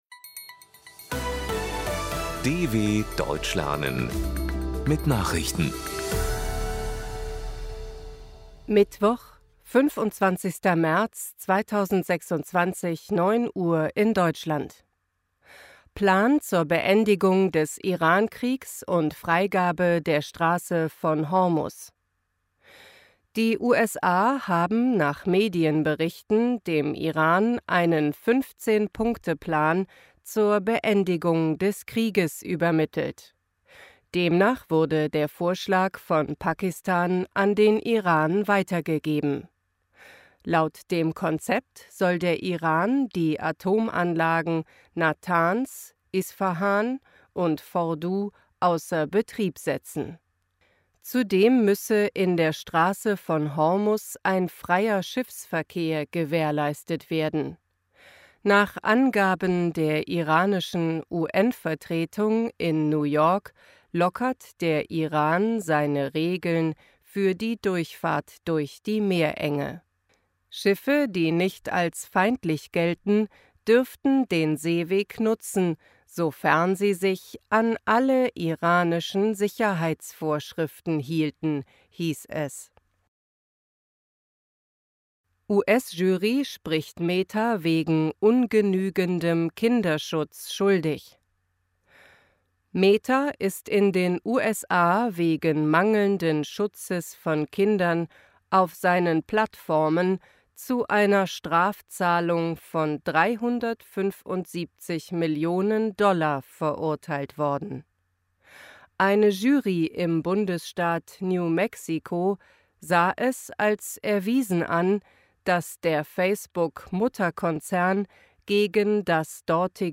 25.03.2026 – Langsam Gesprochene Nachrichten
B2 | Deutsch für Fortgeschrittene: Verbessert euer Deutsch mit aktuellen Tagesnachrichten der Deutschen Welle – für Deutschlerner besonders langsam und deutlich gesprochen.
Mittwoch – als Text und als verständlich gesprochene Audio-Datei.